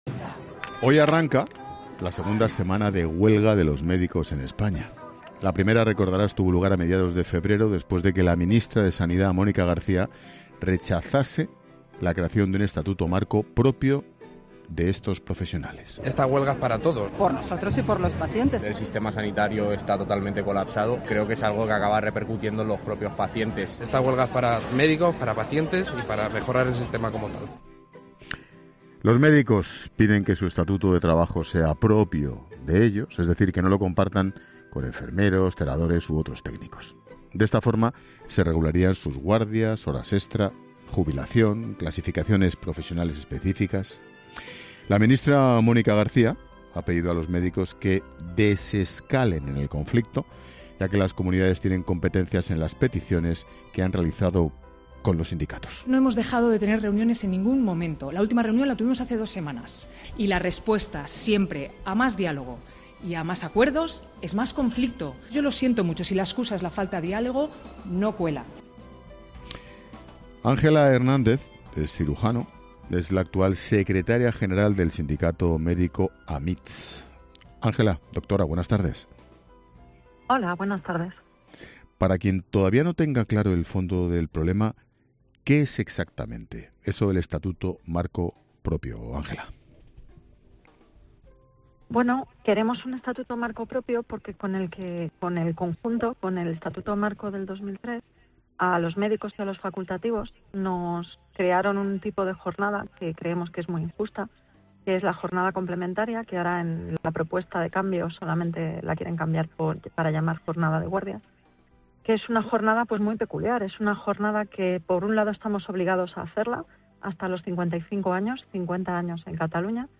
Expósito entrevista